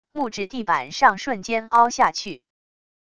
木质地板上瞬间凹下去wav音频